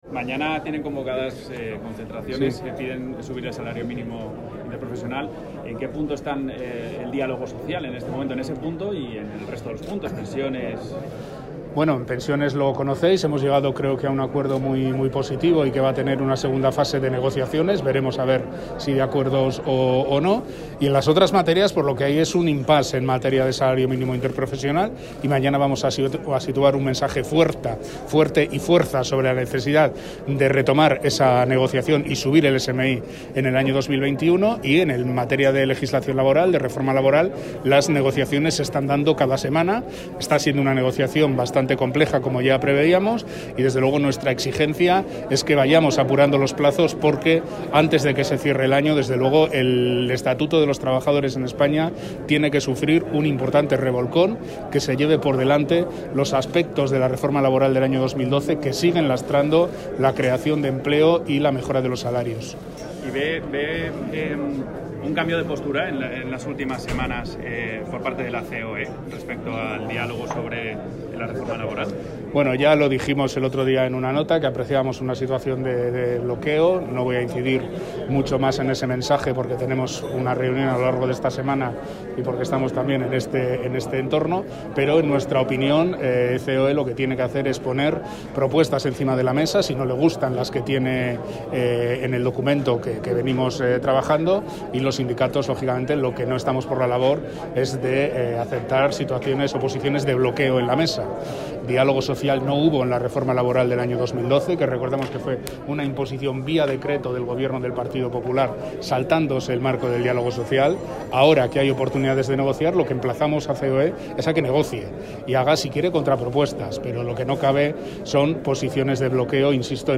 Declaraciones Unai Sordo SMI y bloqueo CEOE